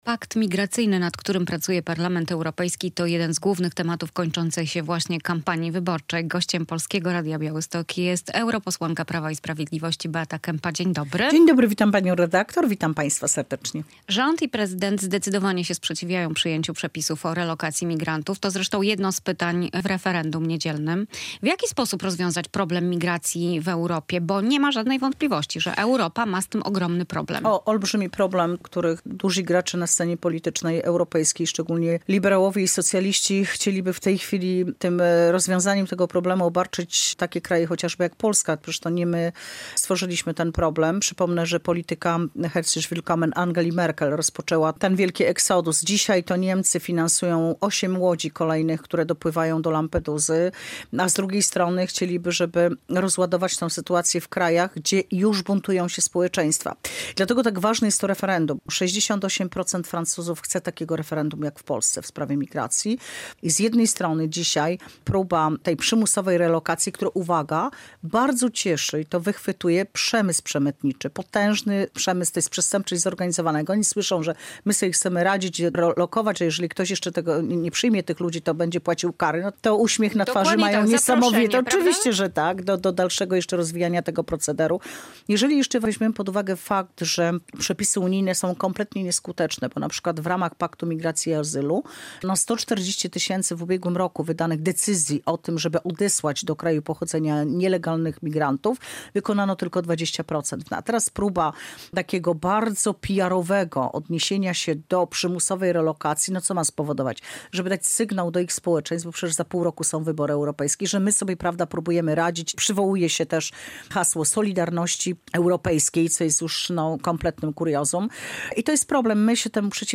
europosłanka Prawa i Sprawiedliwości